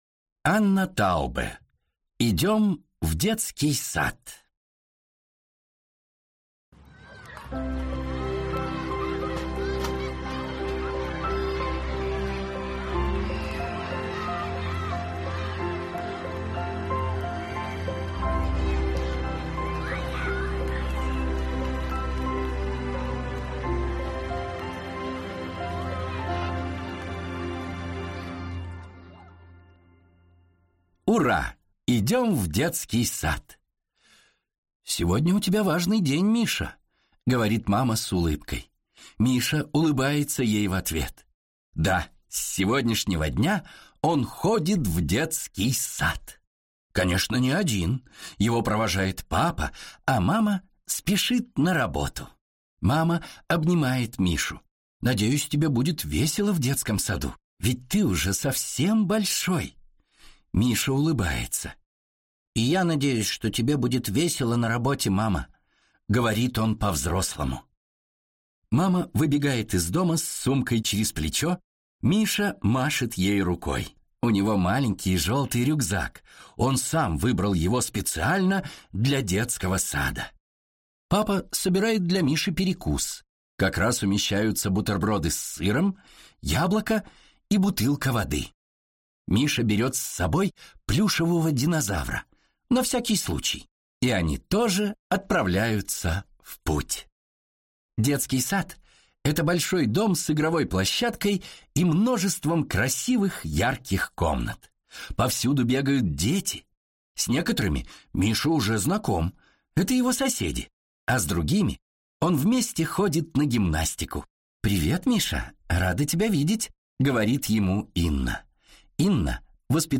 Аудиокнига Идём в детский сад!